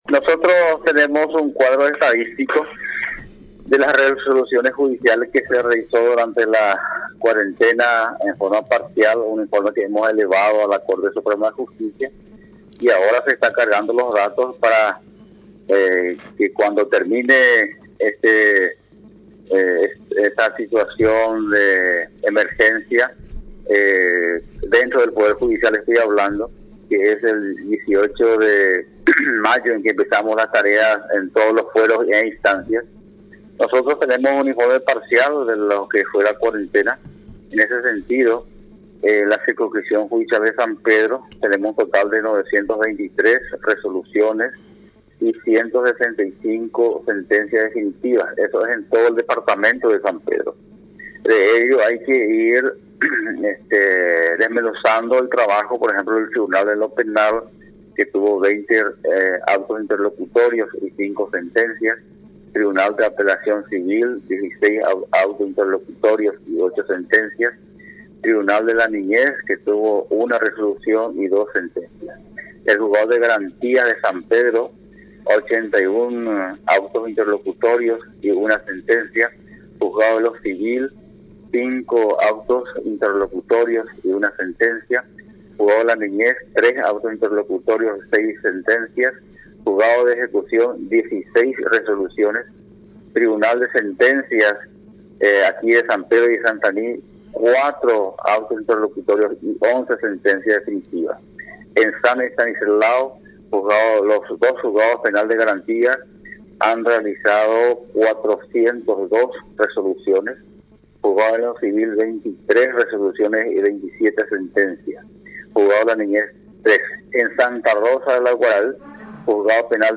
En entrevista a Radio Nacional San Pedro, destacó las labores que se está realizando para agilizar los procesos judiciales y brindar respuestas efectivas a los procesos judiciales a pesar de la Emergencia Sanitaria del COVID-19.